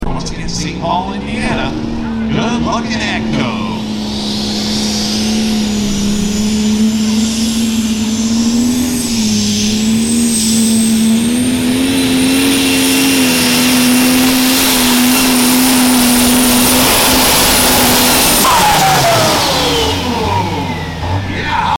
The “Black Tornado Revived” Debuting at The Pullers Championship 2025!!